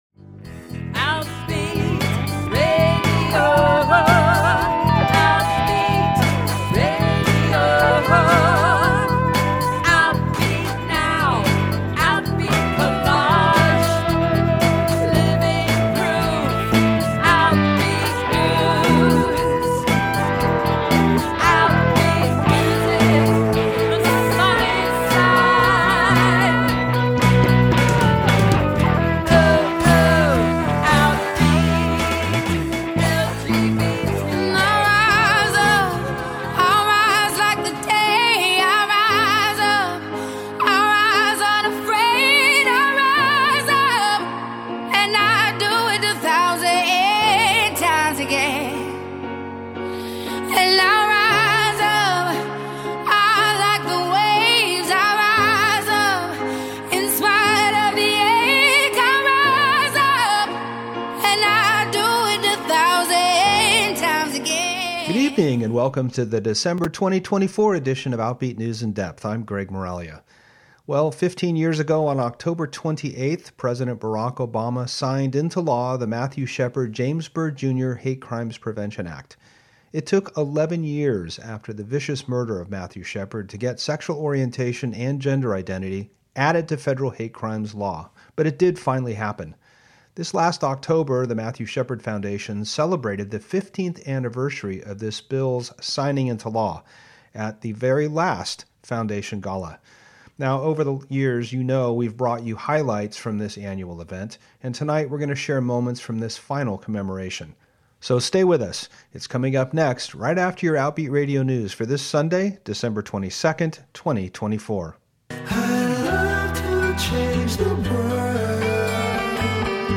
Highlights from the 2024 Matthew Shepard Foundation Gala featuring comments from Judy Shepard, John Berry, and Kal Penn
Over the years we have shared moments from a number of these annual events and this month we bring you highlights from the last one held this October in Denver, Colorado with comments from Judy Shepard and honorees John Berry and Kal Penn.